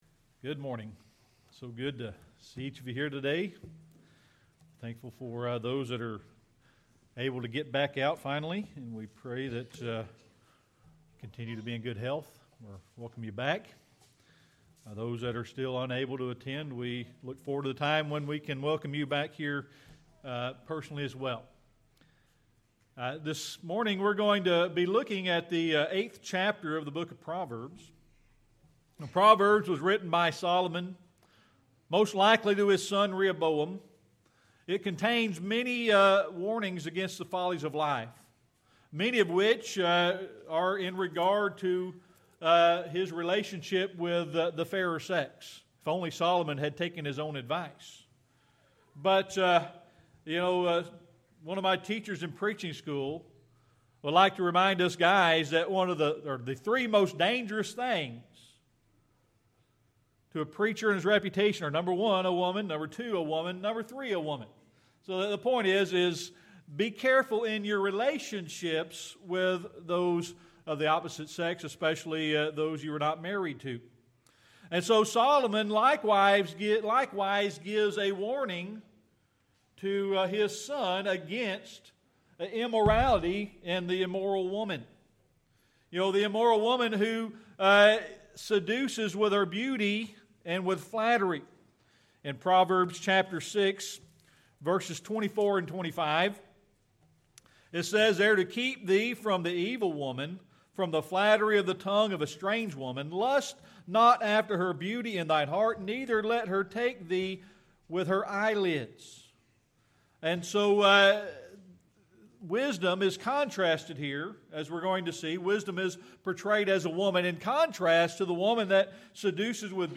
Sermon Archives
Proverbs 8 Service Type: Sunday Morning Worship We're going to look at the 8th chapter of the book of Proverbs.